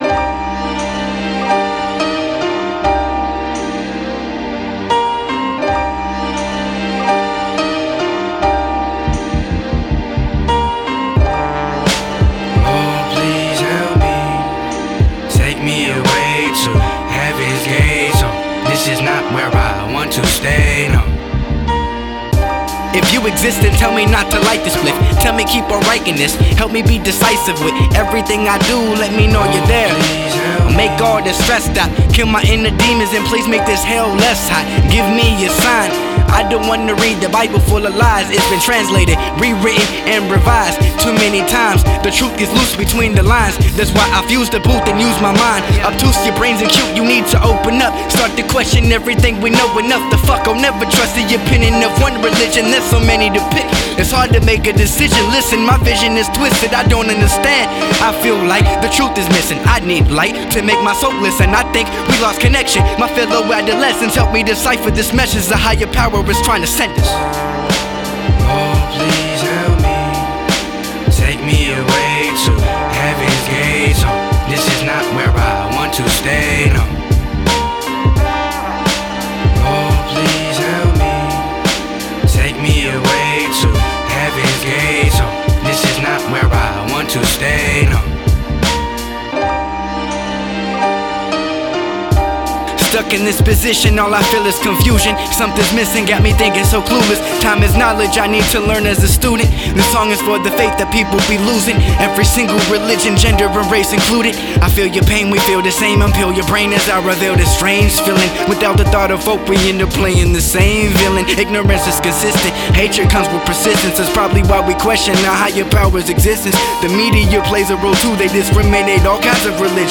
With a tongue-twisting cadence